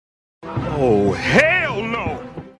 Oh Hell Nononoo Meme sound effects free download